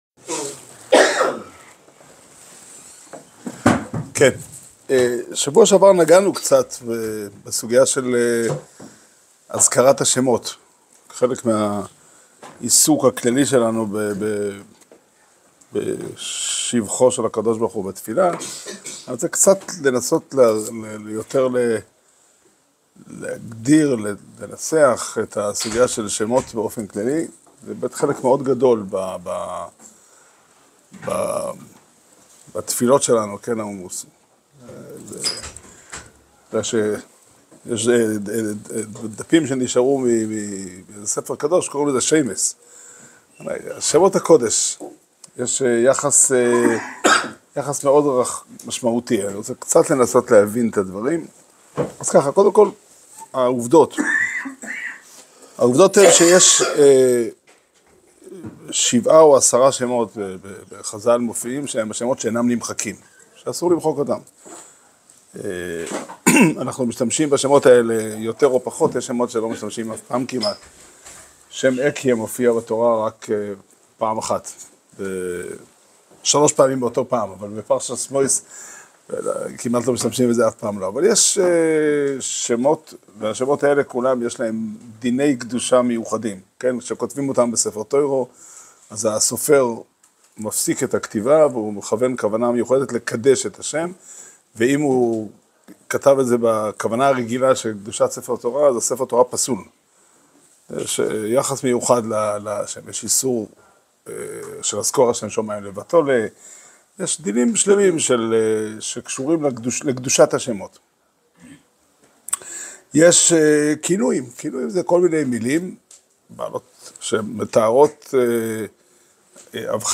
שיעור שנמסר בבית המדרש פתחי עולם בתאריך ד' כסלו תשפ"ה